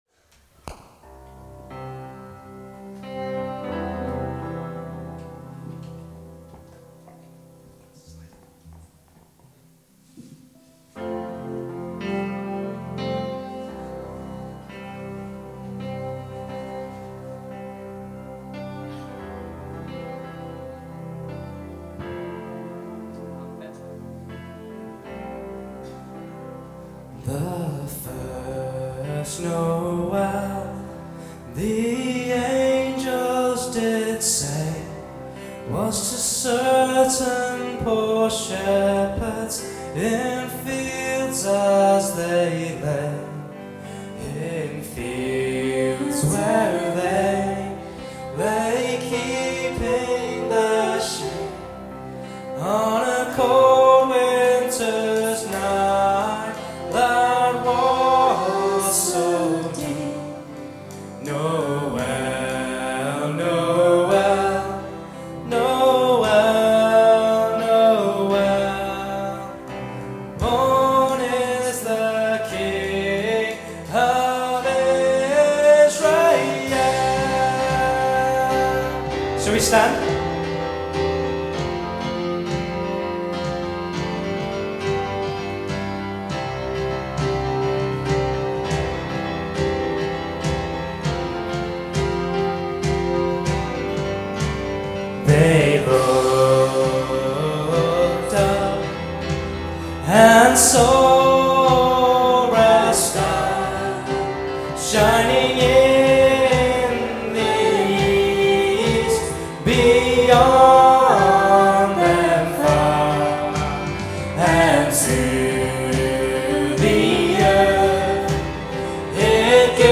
Christmas Special: Christmas Carols – Birmingham Chinese Evangelical Church